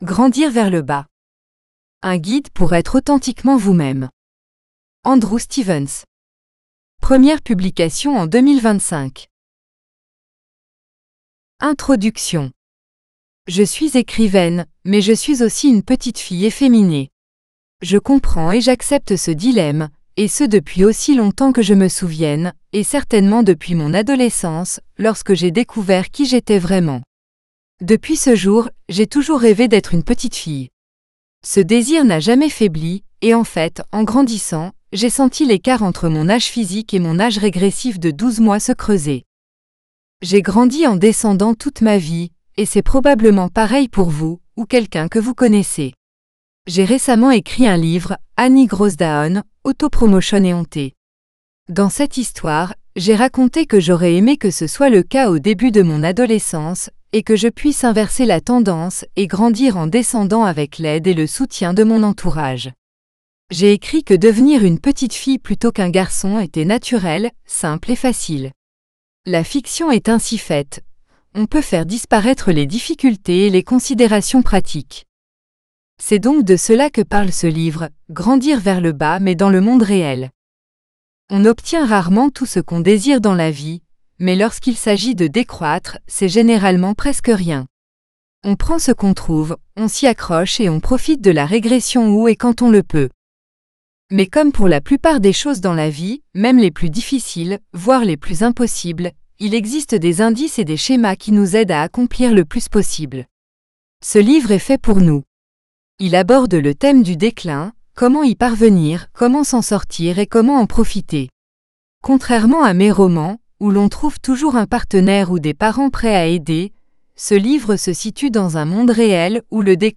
Growing Down FRENCH (female voice AUDIOBOOK): $US5.75